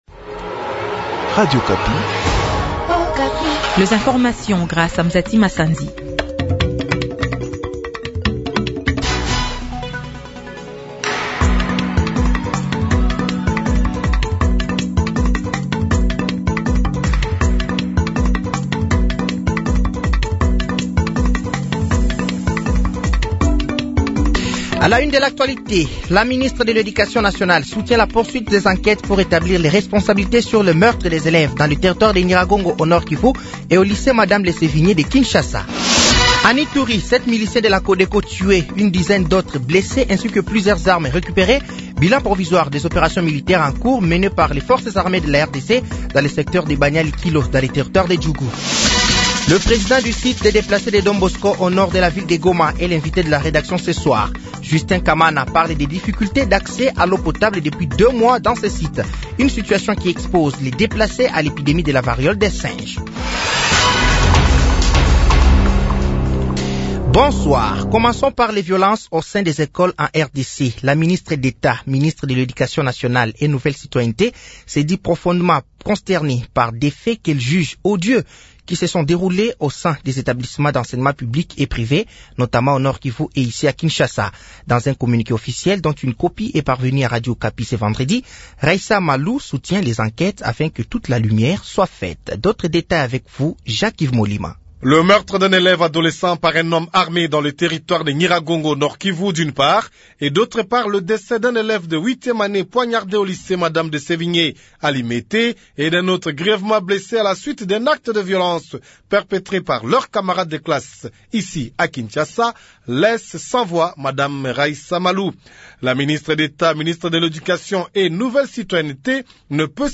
Journal Soir
Journal français de 18h de ce vendredi 13 septembre 2024